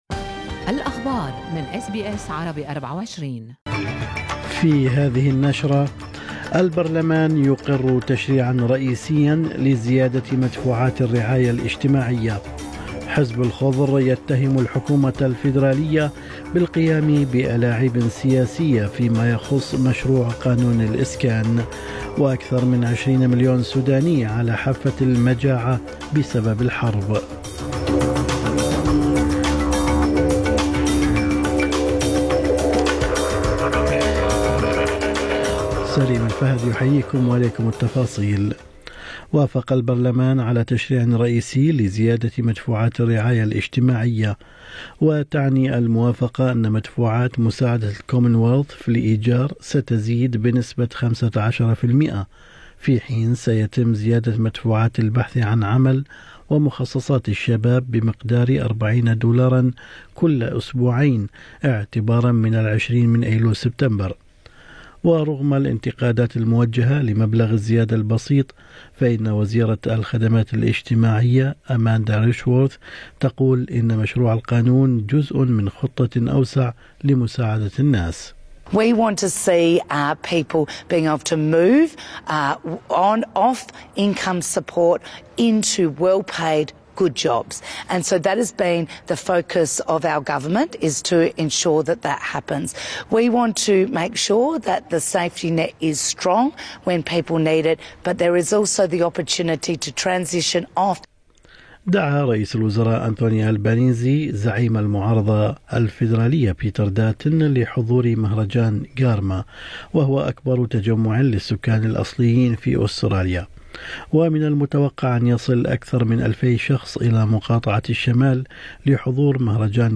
نشرة اخبار الصباح 3/8/2023